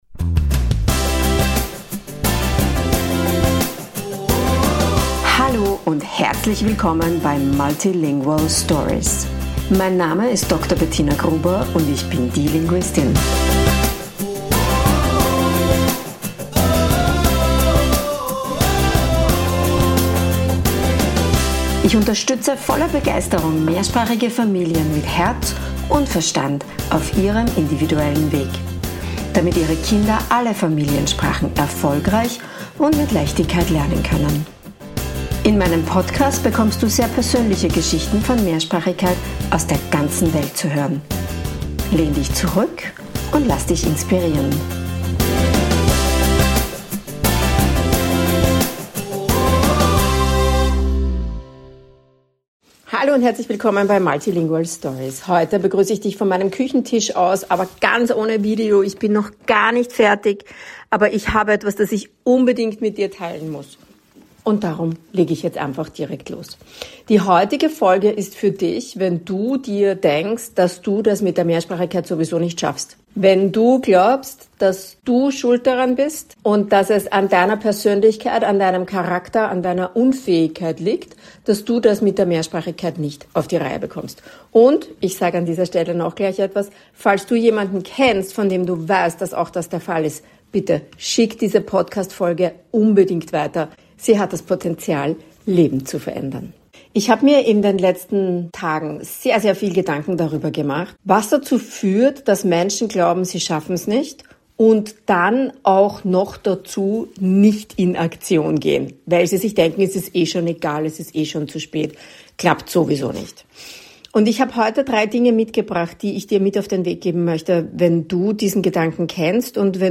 In dieser ungeplanten Solo-Folge von Multilingual Stories spreche ich direkt vom Küchentisch – ohne Video, noch nicht fertig gemacht, aber mit etwas, das ich unbedingt mit dir teilen muss.